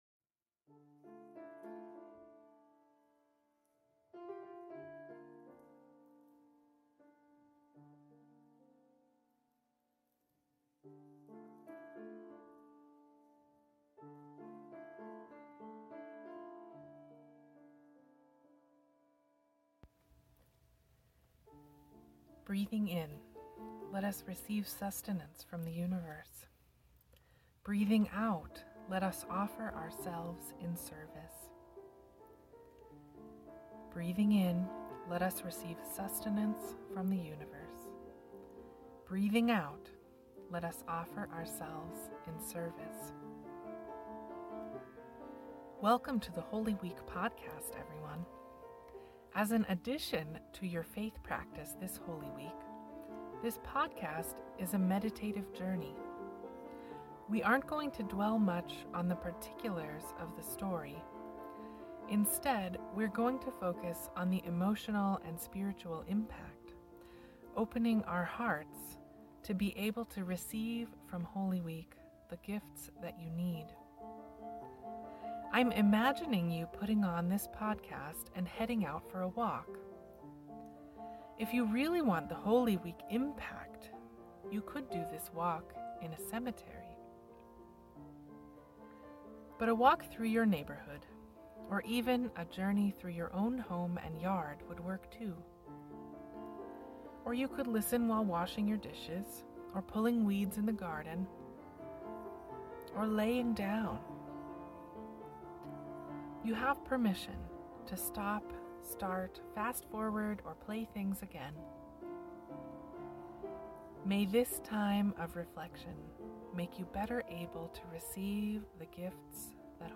This year, as an addition to the Holy Week services online and the outdoor Easter Sunrise, there’s a 30-minute podcast to help you get in the emotional space for Easter. A mix of meditative prompts and music, the podcast would be a great soundtrack to a walk through a cemetery, a stroll along the water, a jaunt through your neighborhood, half an hour of chores around the house, or even a lie-down.